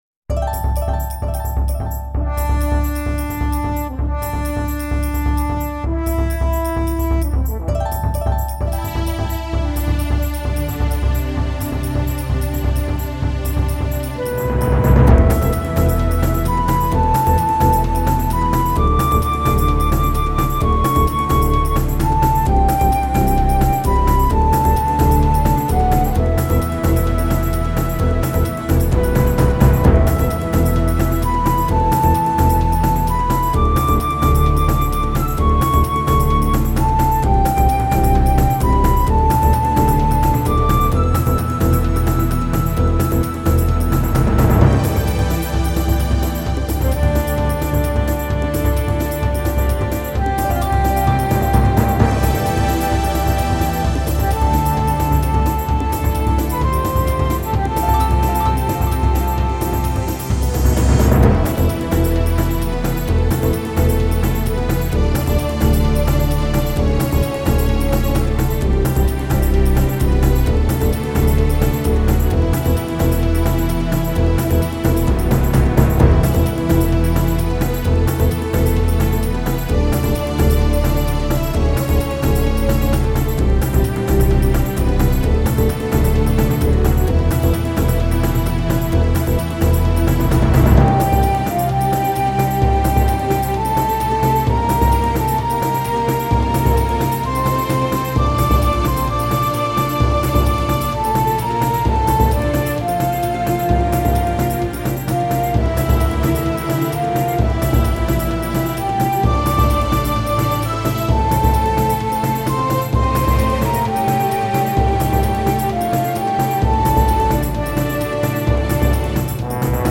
A remix